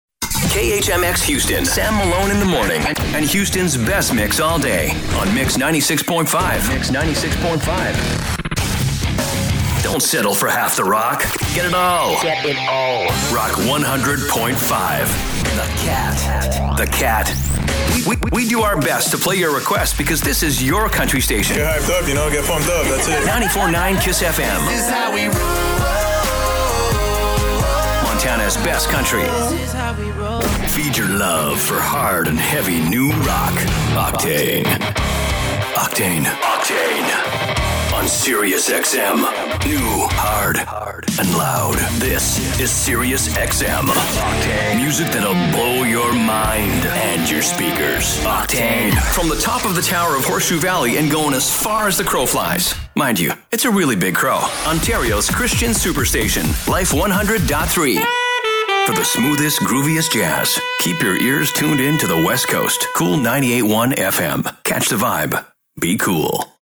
Young Adult
Middle Aged
18+ yrs full time Voice Actor with a Pro home studio and Source Connect.